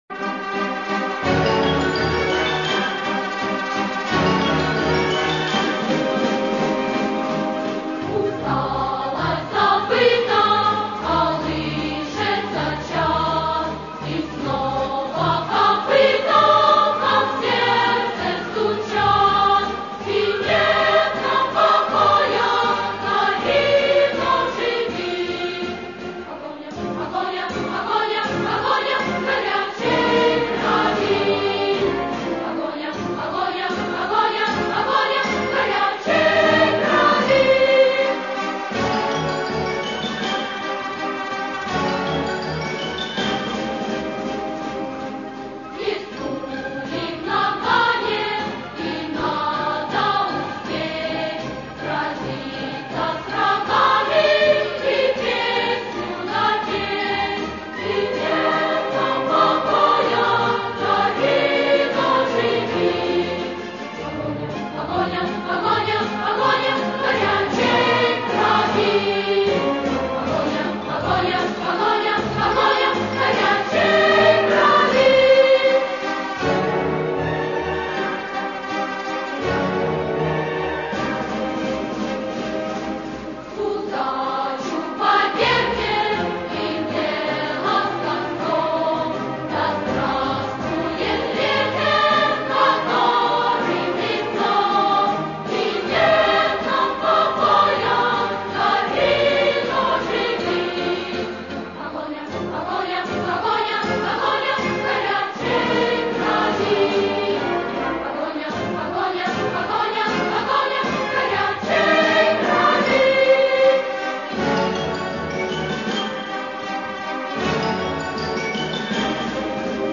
Песня из кинофильма